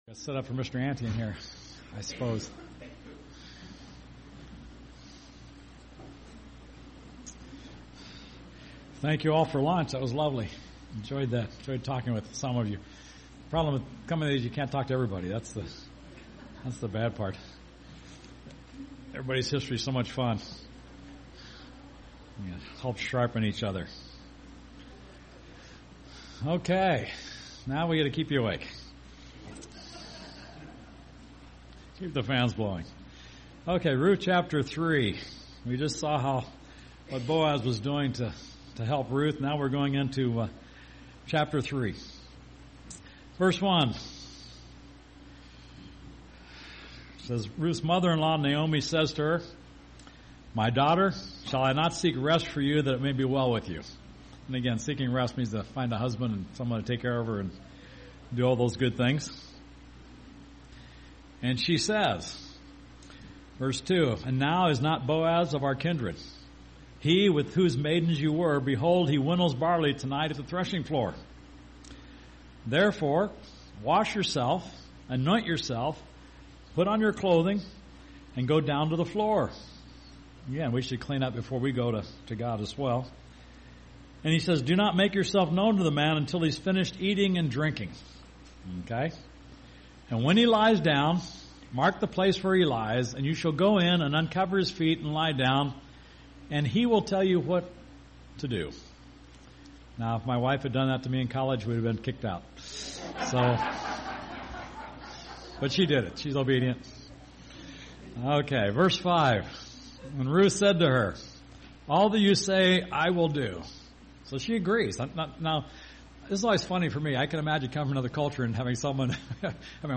ABC Continuing Education Sampler UCG Sermon Transcript This transcript was generated by AI and may contain errors.